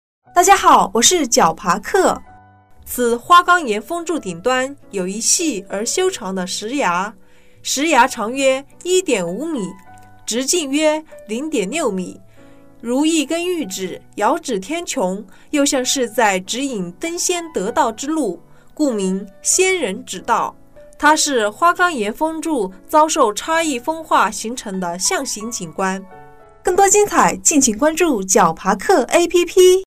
仙人指道----- 66666 解说词: 此花岗岩峰柱顶端有一细而修长的石芽（长约1.5米，直径约0.6米），如一只玉指，遥指天穹，又像是在指引登仙得道之路，故名“仙人指道”。